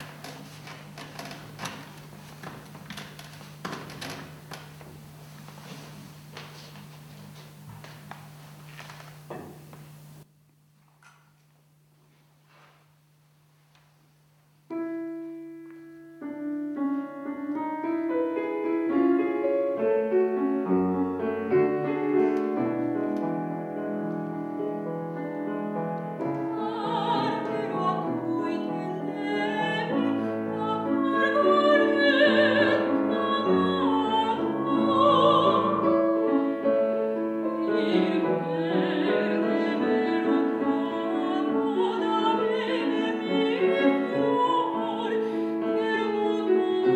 III OTTOBRE MUSICALE A PALAZZO VALPERGA
soprano
pianoforte - Giuseppe Martucci Pianto Antico (Carducci)